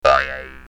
bounce3.ogg